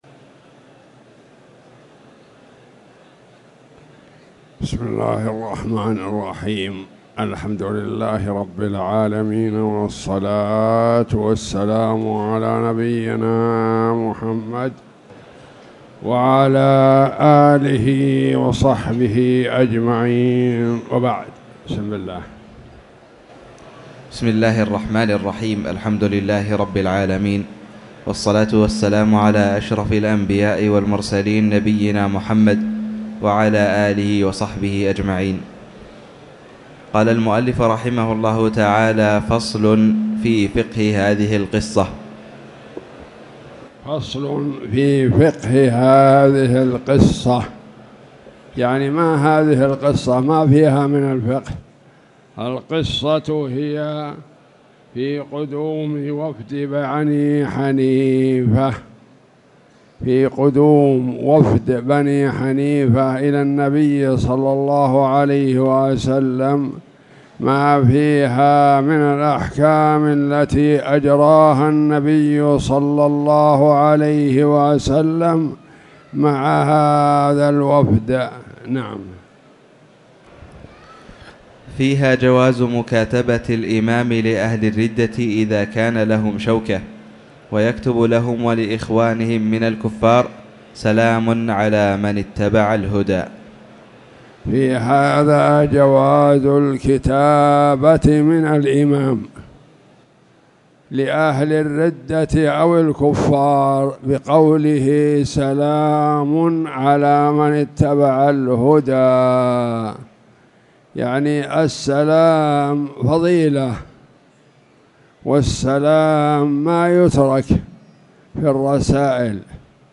تاريخ النشر ١١ جمادى الآخرة ١٤٣٨ هـ المكان: المسجد الحرام الشيخ